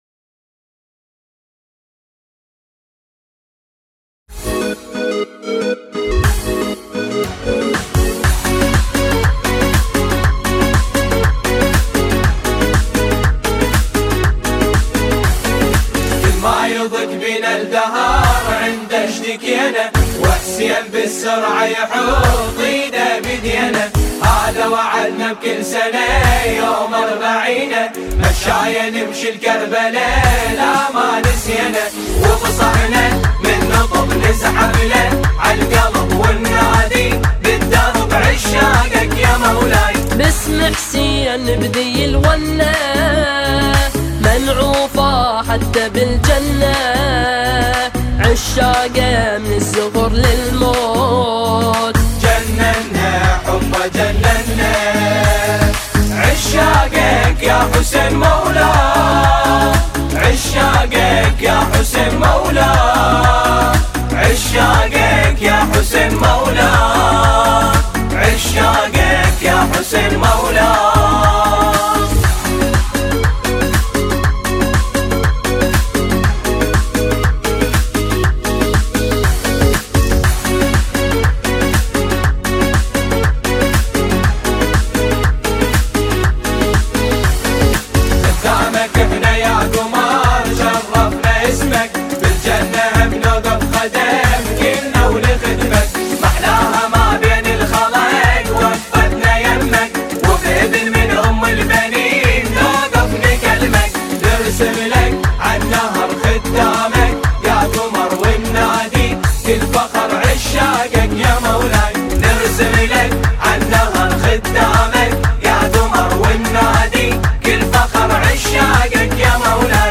ژانر: سرود ، سرود انقلابی ، سرود مذهبی ، سرود مناسبتی